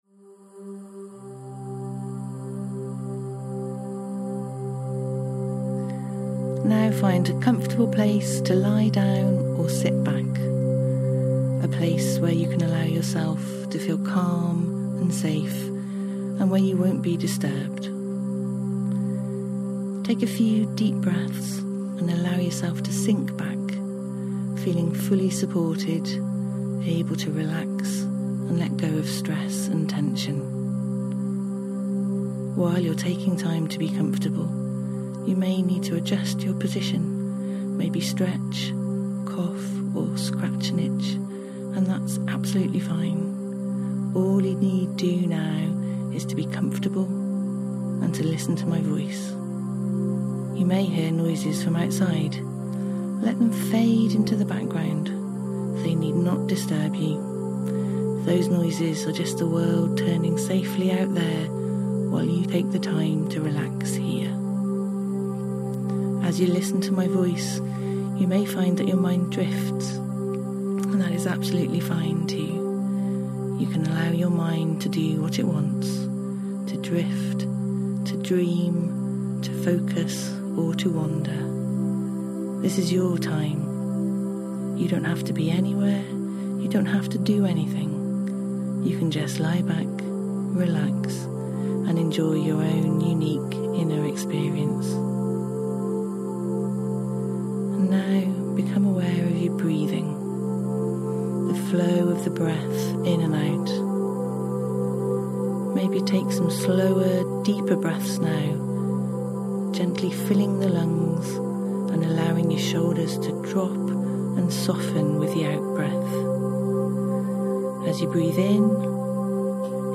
Introductory Hypnotherapy Recording